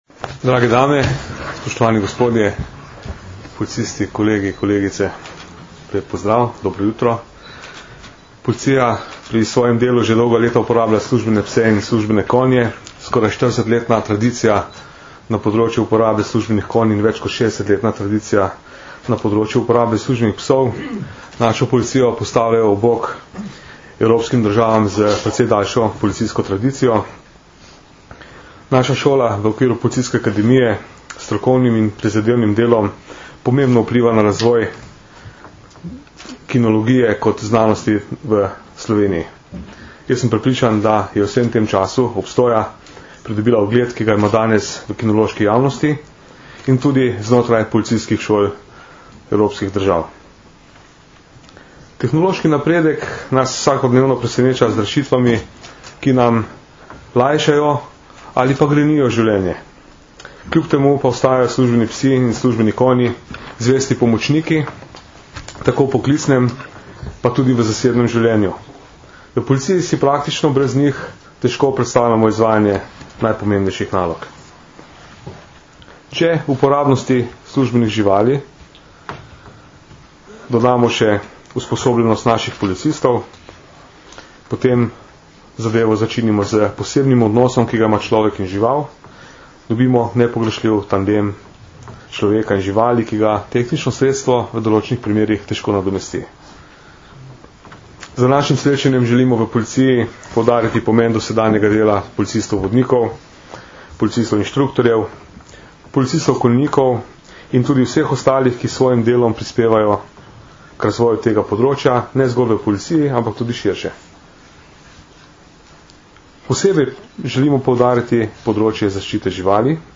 V Policijski akademiji v Tacnu smo danes, 11. novembra 2010, organizirali že drugi strokovni posvet s področja uporabe živali in njihove zaščite.
Udeležence posveta je nagovoril tudi direktor Uprave uniformirane policije Danijel Žibret.
Zvočni posnetek govora Danijela Žibreta (mp3)